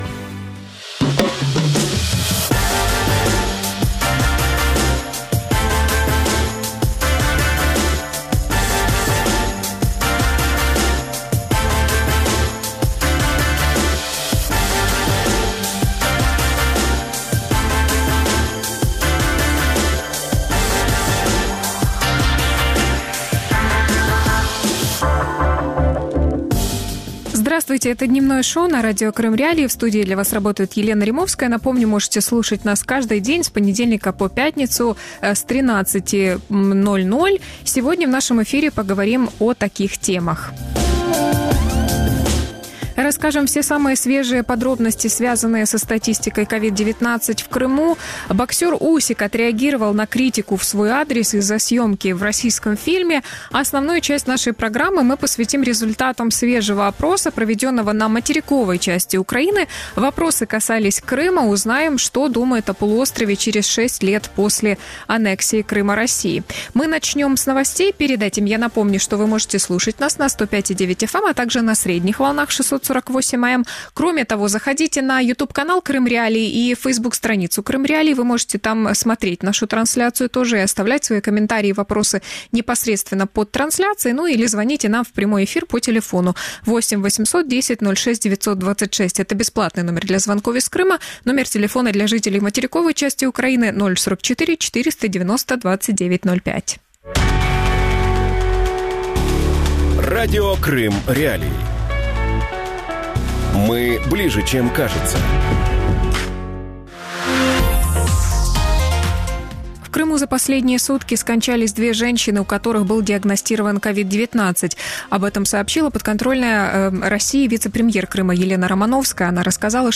Транспорт в Крым – да, возвращение силой – нет. Материковые украинцы о Крыме | Дневное ток-шоу